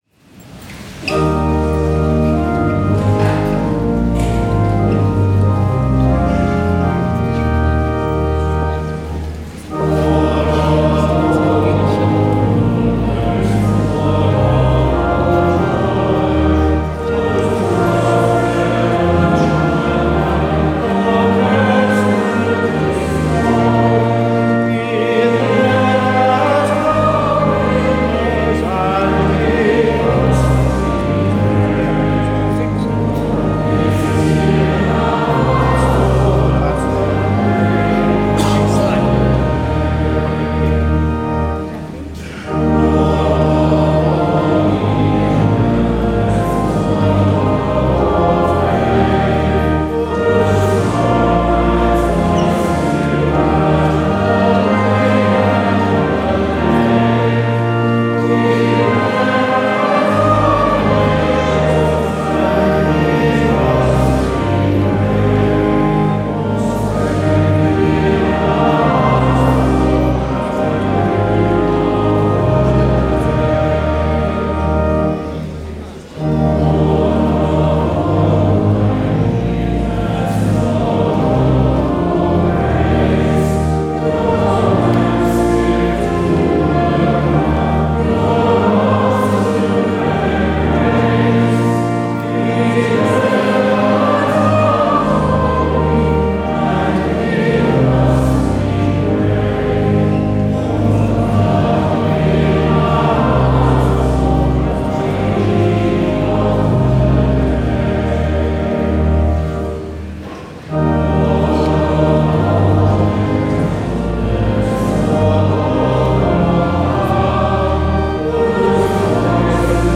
Diocesan Rite of Election
First Sunday of Lent
Hymn – Lord of All Hopefulness
Organ